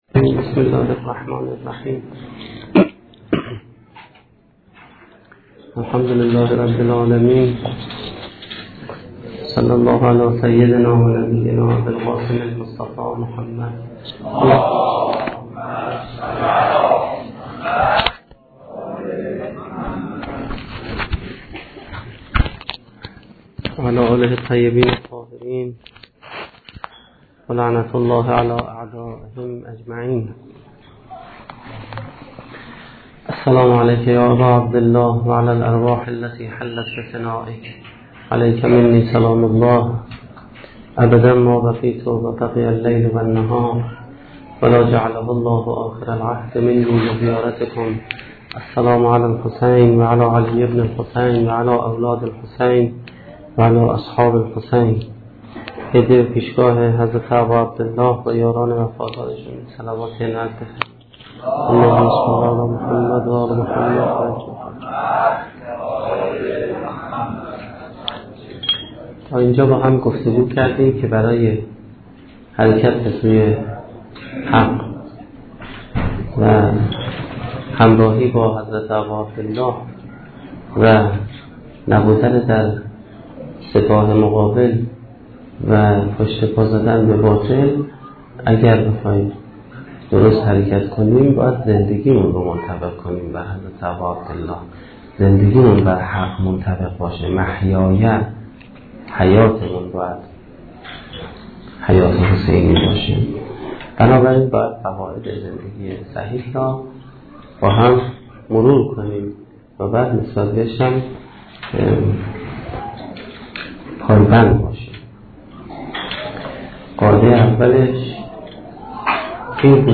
سخنرانی پنجمین شب دهه محرم1435-1392